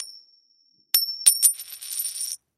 На этой странице собраны разнообразные звуки монет: от звонкого падения одиночной монеты до гула пересыпающихся денежных масс.
Звук подкидывания и падения монеты